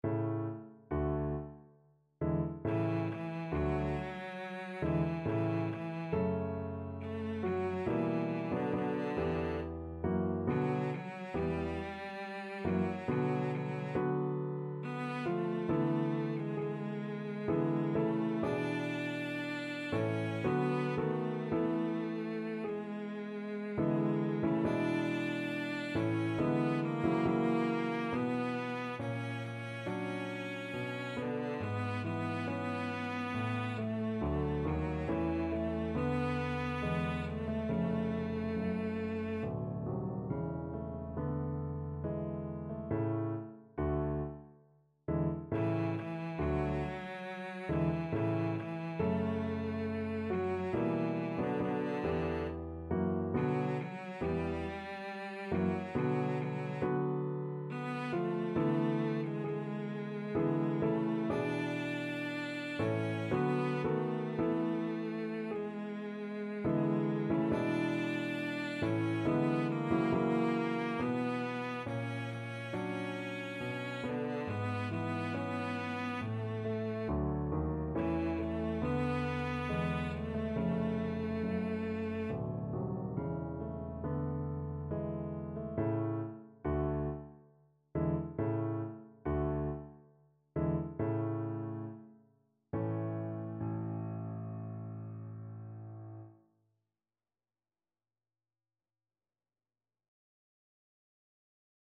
Cello version
3/4 (View more 3/4 Music)
Larghetto =69
Classical (View more Classical Cello Music)